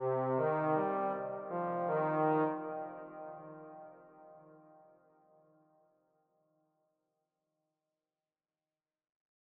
melodic_champ_arpeggio.wav